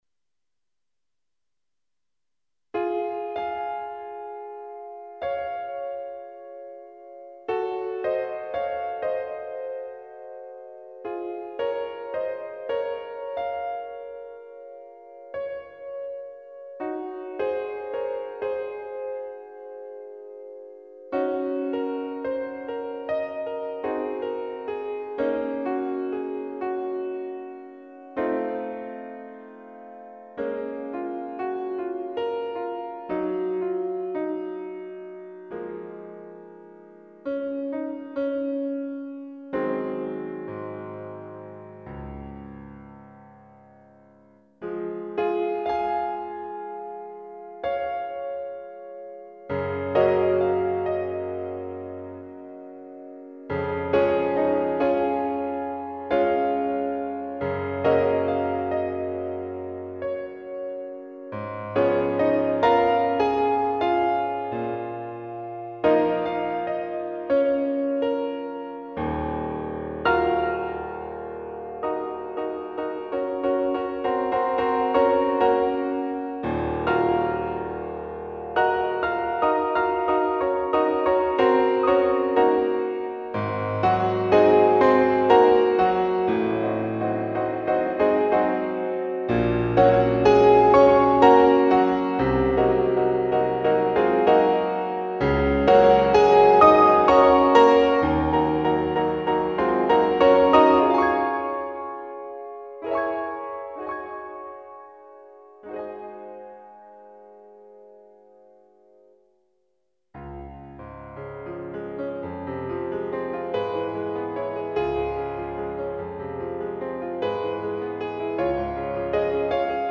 CLASSICAL MUSIC